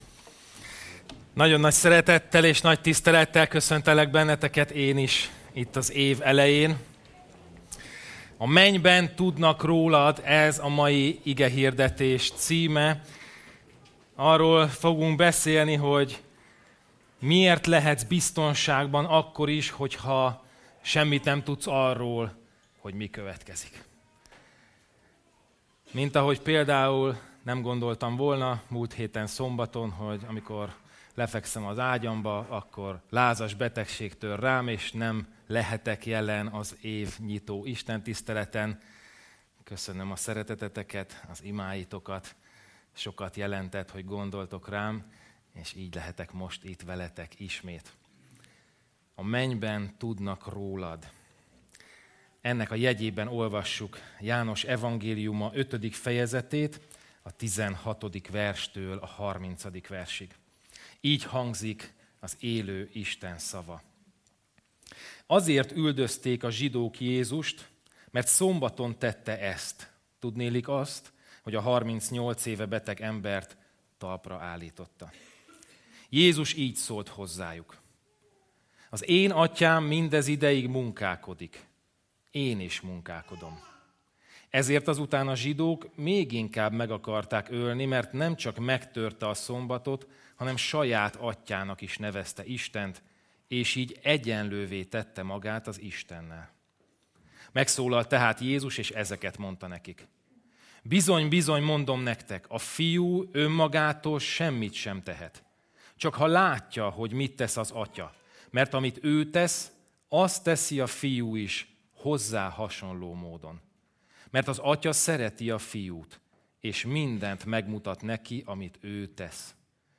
Igehirdetések - Trinity Baptista Gyülekezet